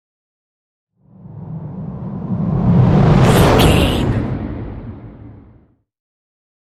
Dramatic whoosh to hit trailer
Sound Effects
Atonal
intense
tension
woosh to hit